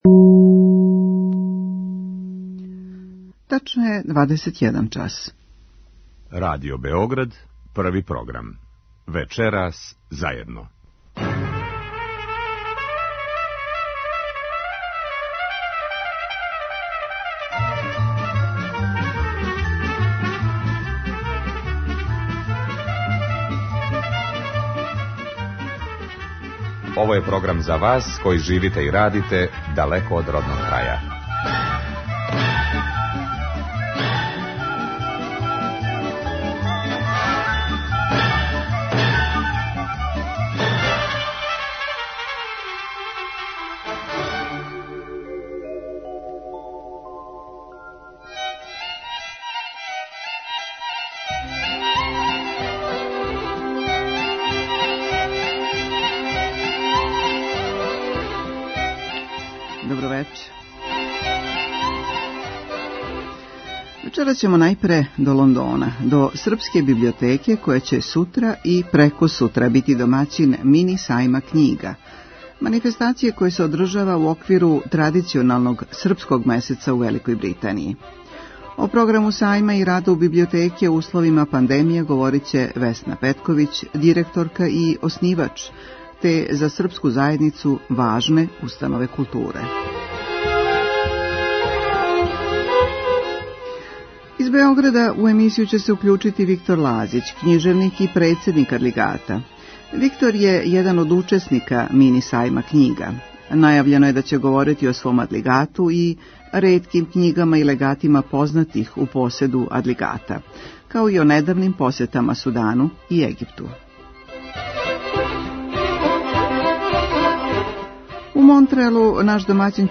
Емисија магазинског типа која се емитује сваког петка од 21 час.